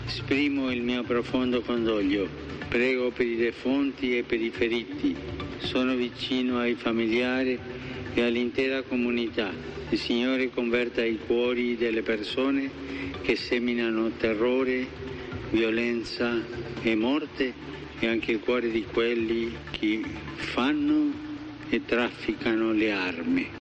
Jorge Bergoglio también expresó su pésame a los familiares de las víctimas, a los heridos y a la población egipcia, y les aseguró que están en sus oraciones, momentos antes de rezar el Ángelus en la Plaza de San Pedro del Vaticano.